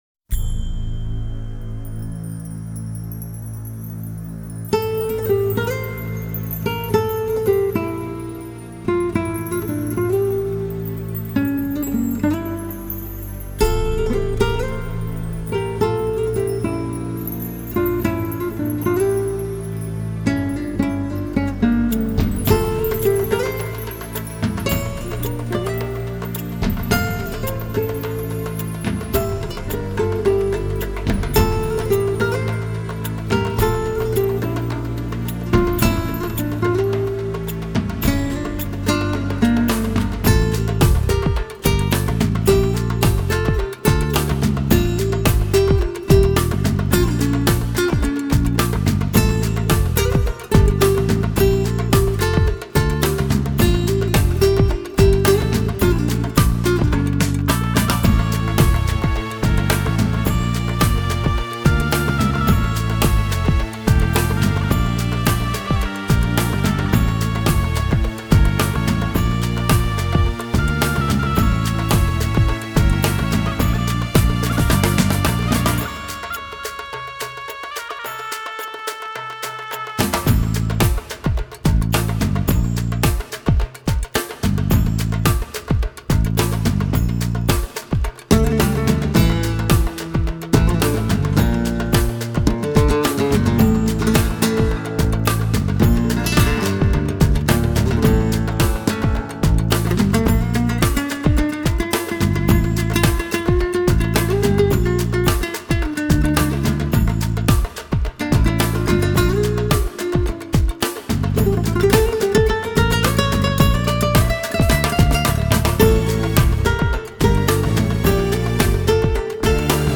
类型:Flamenco